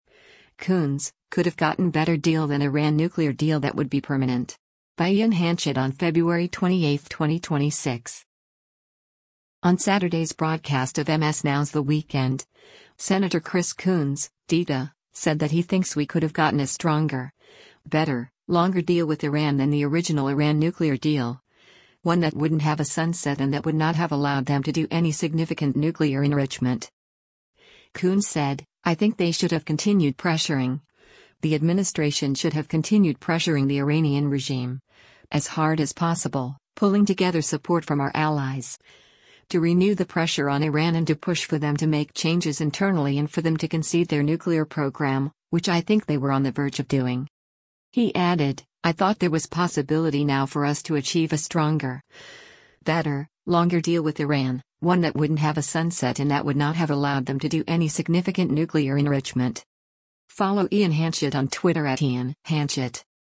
On Saturday’s broadcast of MS NOW’s “The Weekend,” Sen. Chris Coons (D-DE) said that he thinks we could have gotten “a stronger, better, longer deal with Iran” than the original Iran nuclear deal, “one that wouldn’t have a sunset and that would not have allowed them to do any significant nuclear enrichment.”